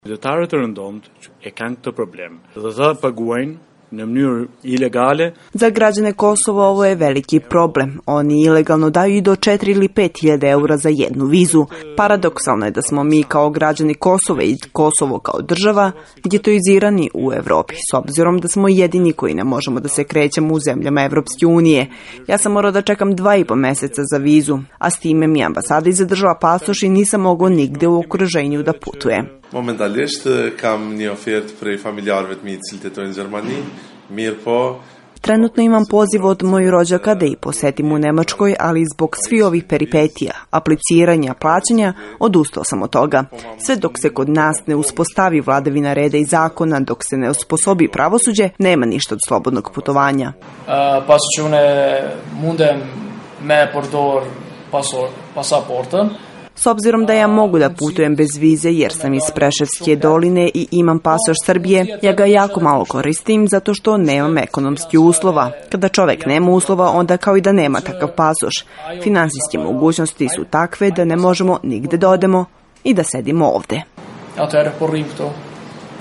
I sve dok do toga ne dođe, Kosovari ostaju jedini getoizirani građani u regionu, kažu stanovnici Prištine: